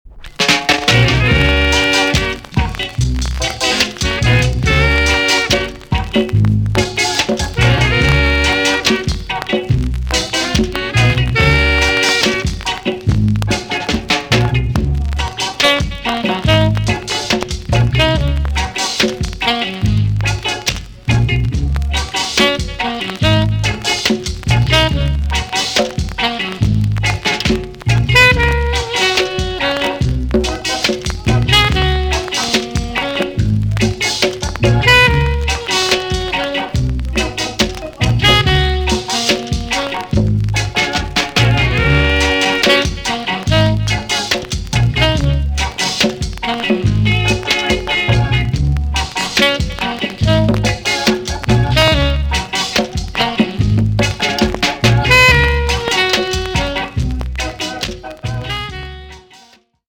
VG+~VG ok 軽いチリノイズがあります。
B.SIDE INST NICE TOO!!